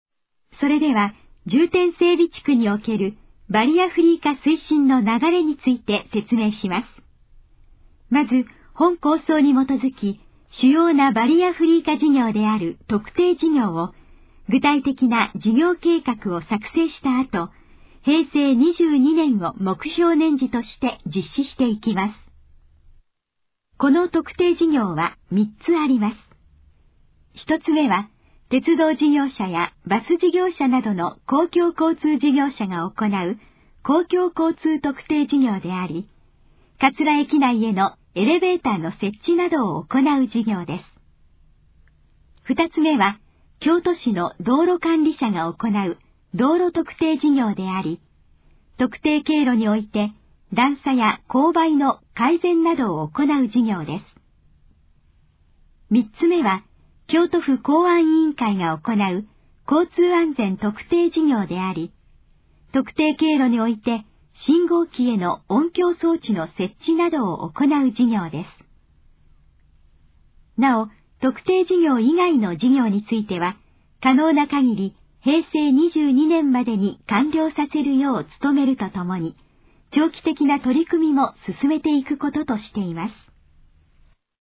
このページの要約を音声で読み上げます。
ナレーション再生 約170KB